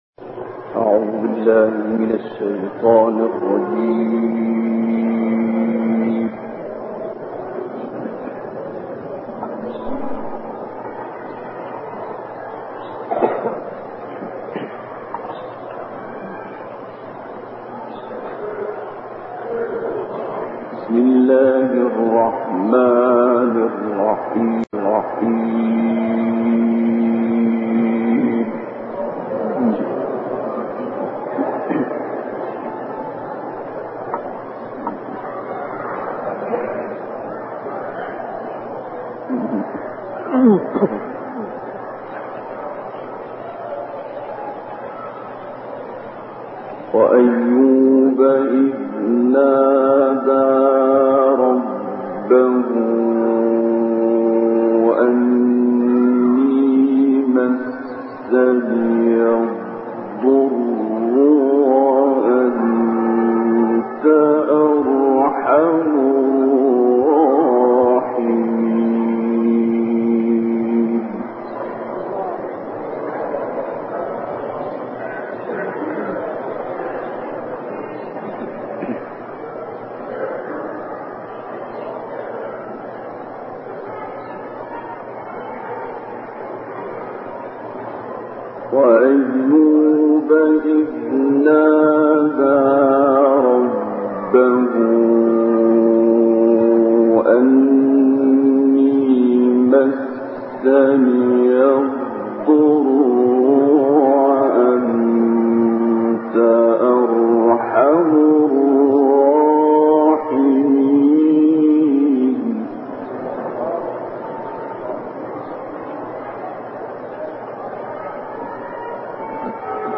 تلاوت آیاتی از سوره انبیاء توسط استاد عبدالباسط محمد عبدالصمد